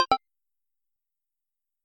SFX_UI_Confirm.mp3